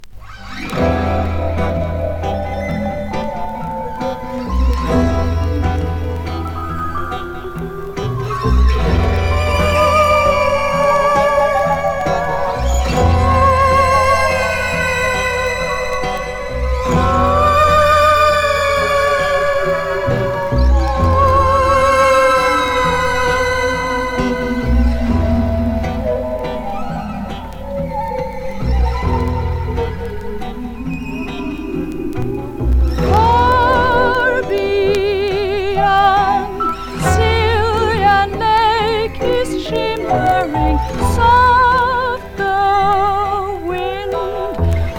なんと、25回の多重録音で創出した凄み溢れる世界。
Jazz, Pop, Experimental　USA　12inchレコード　33rpm　Stereo